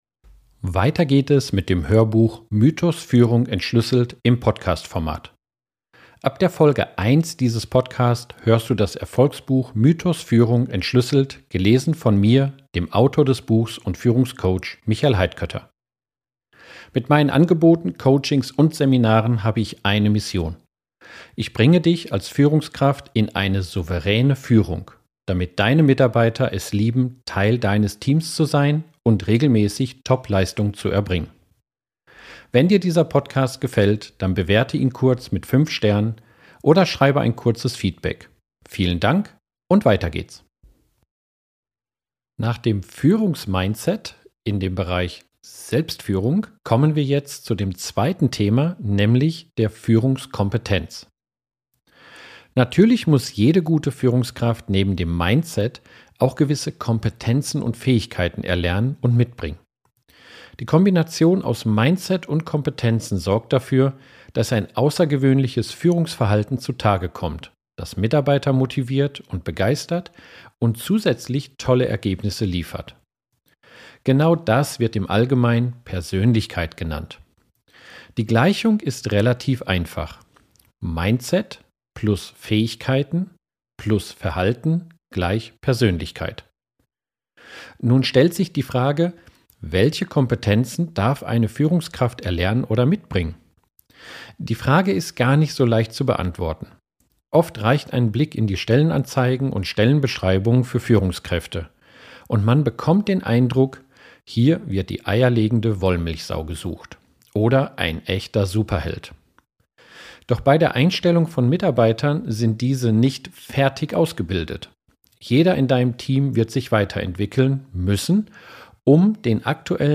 Du erhältst das vollständige Hörbuch gratis und ganz bequem im Podcast-Format zum Hören im Auto, auf Reisen, beim Sport oder im Café.